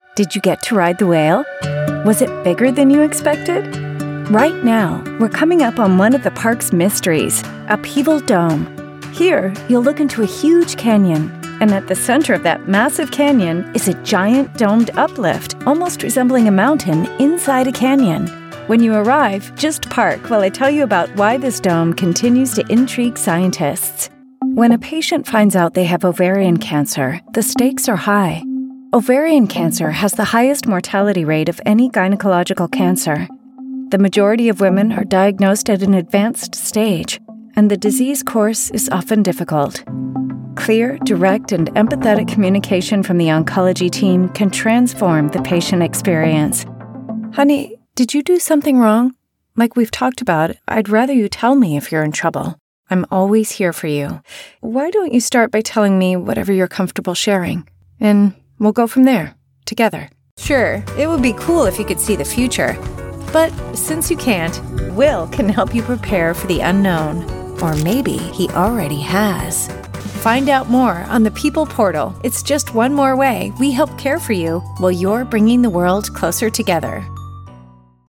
Fresh, friendly, smart, and approachable female voice actor.
e-learning demo ↓